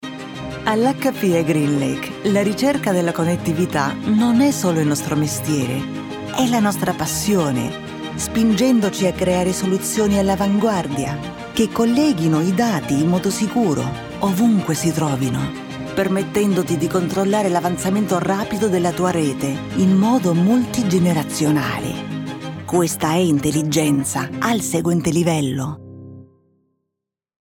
A voice of dusky warmth, intelligence, and effortless elegance—created for brands that wish to be felt as much as heard.
From sustainable fashion to premium technology— this Italian demo reveals a voice that is both expressive and refined, where beauty, innovation, and authenticity meet.
Recording from a professional Studiobricks home studio in Barcelona.